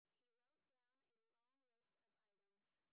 sp11_street_snr30.wav